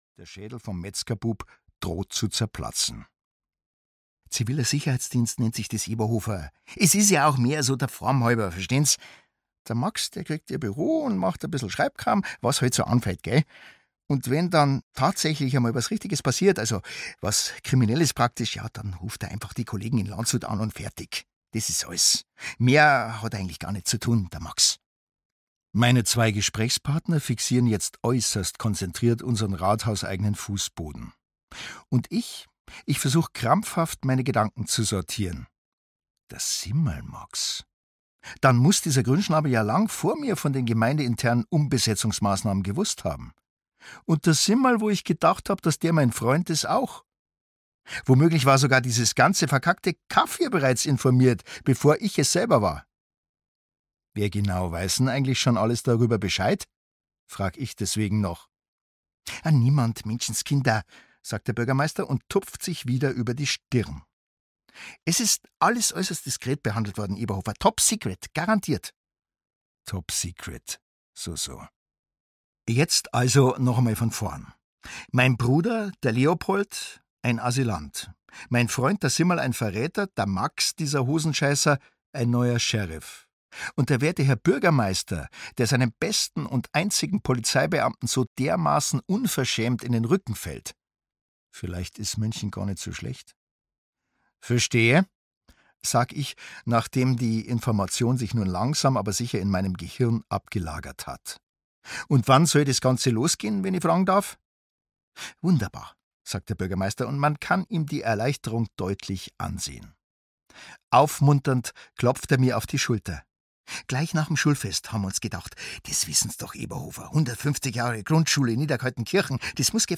Sauerkrautkoma - Rita Falk - Hörbuch